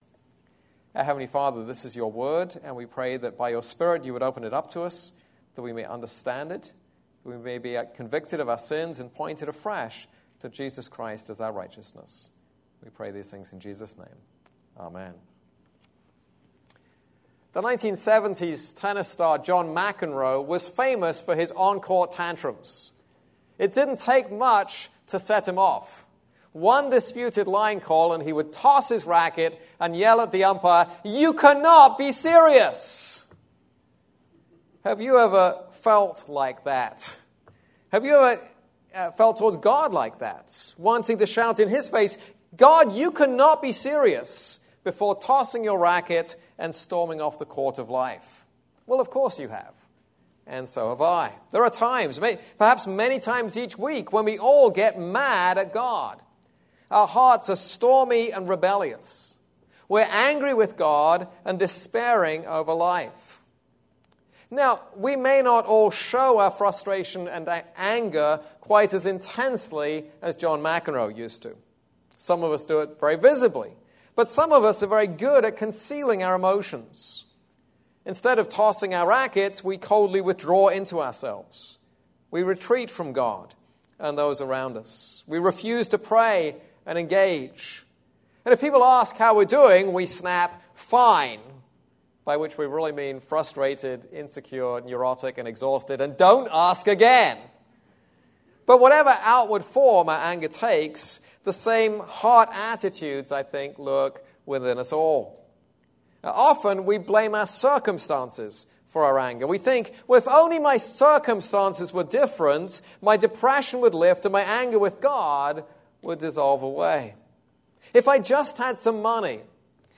This is a sermon on Jonah 4:2-11.